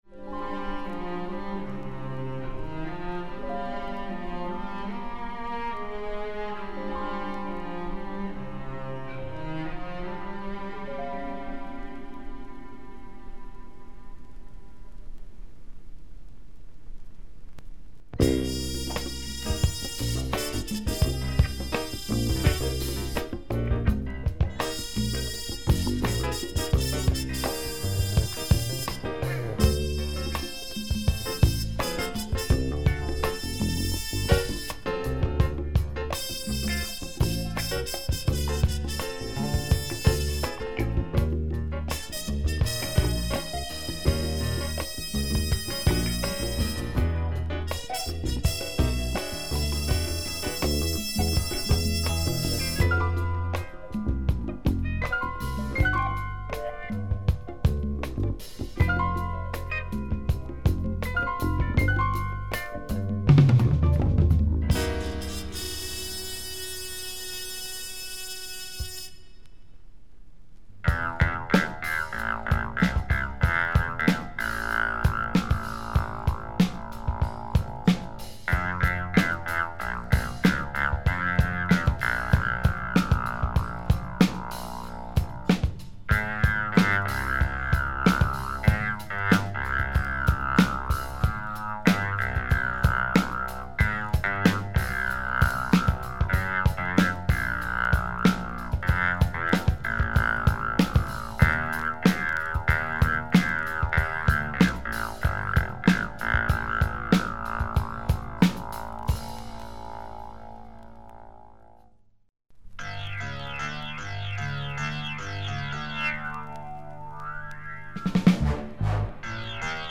A groovy Japanese soundtrack. Laidback groove
which turns into killer breaks.